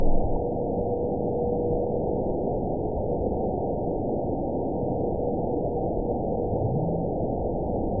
event 920205 date 03/05/24 time 05:43:29 GMT (1 year, 9 months ago) score 6.63 location TSS-AB05 detected by nrw target species NRW annotations +NRW Spectrogram: Frequency (kHz) vs. Time (s) audio not available .wav